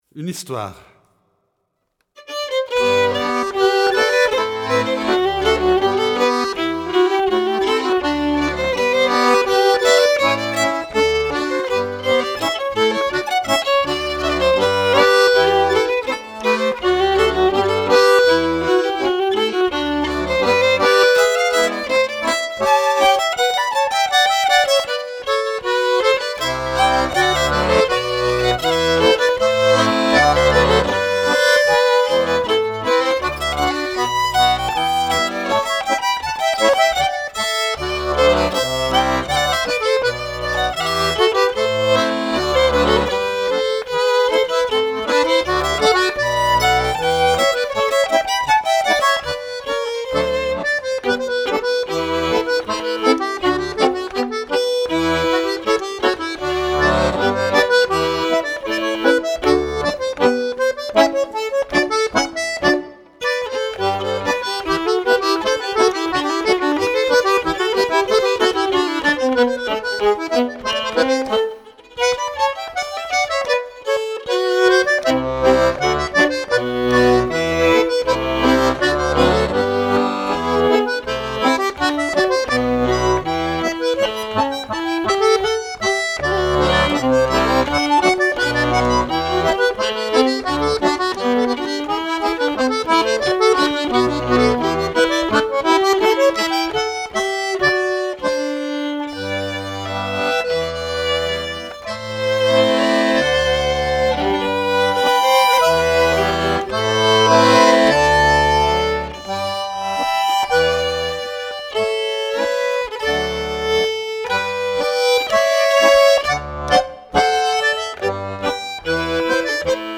violon